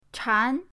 chan2.mp3